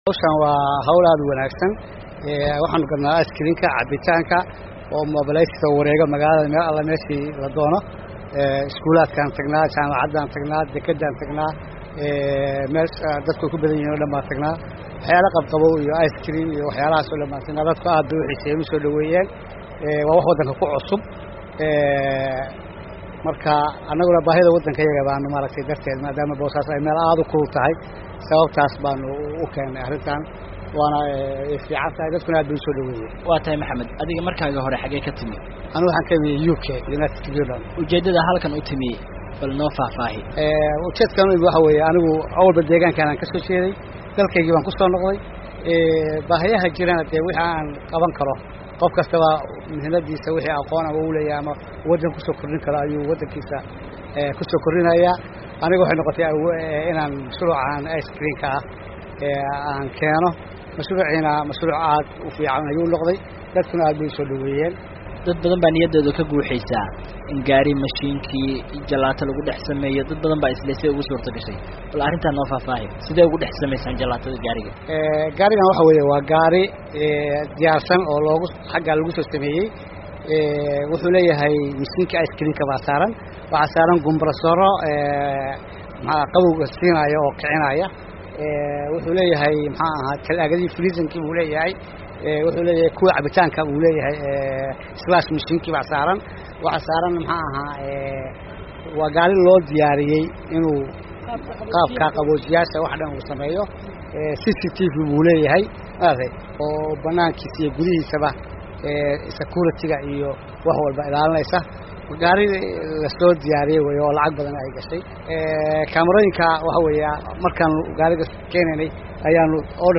Warbixin: Ganacsiga Boosaaso